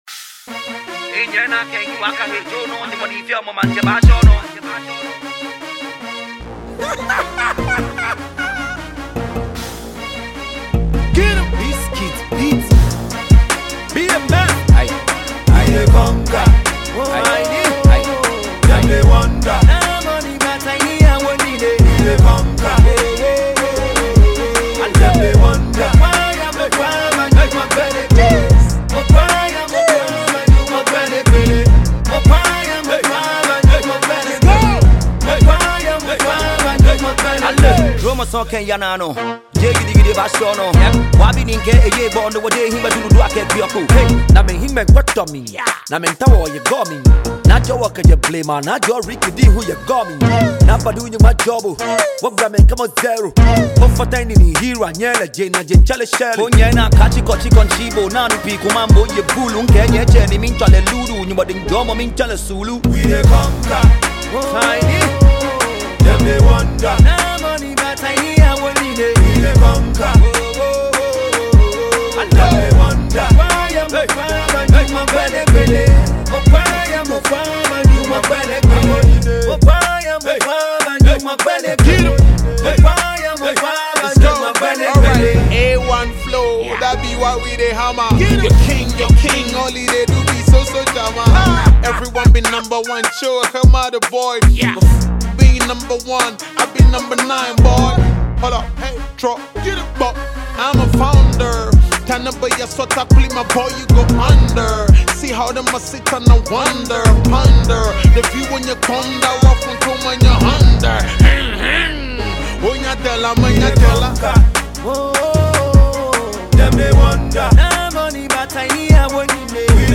Veteran Ghanaian rapper
electrifying new track